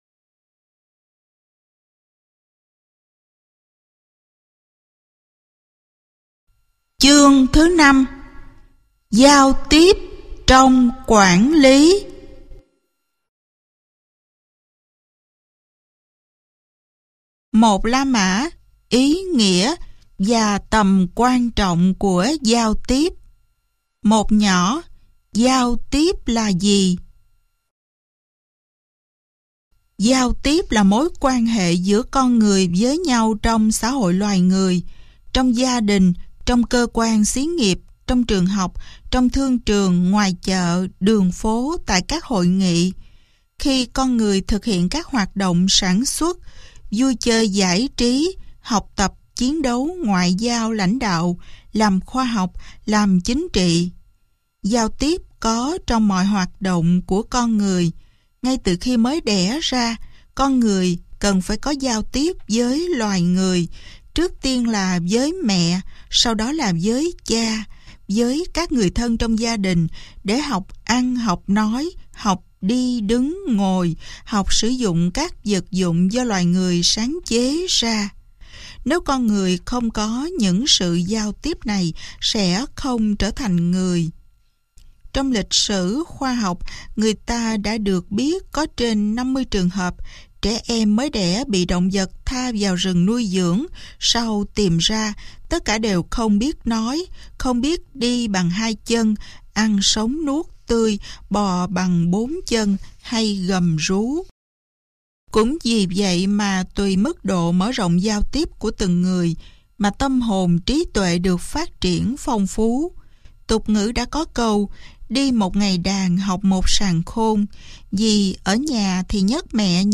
Sách nói Giáo trình tâm lý học quản lý - Sách Nói Online Hay